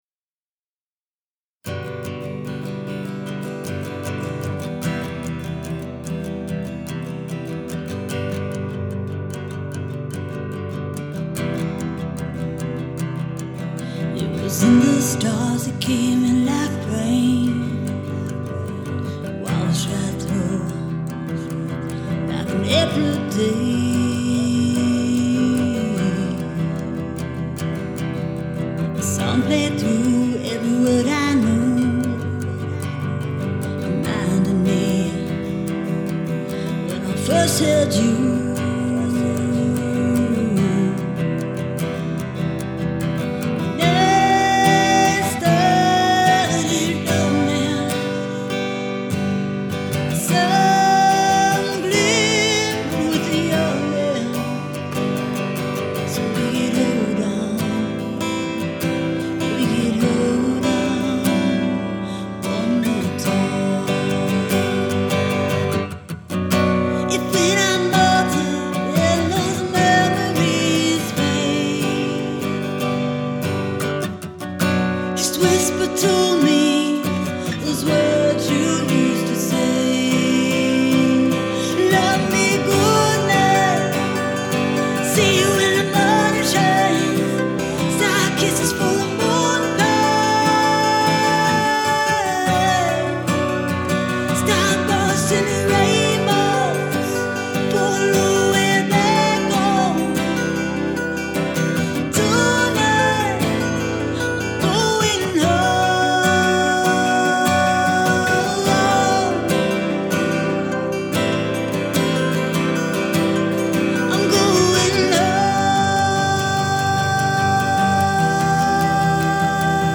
Raw, soulful vocals, emotional lyrics of poetry and storytelling-fueled by vintage and timeless Rock N Roll.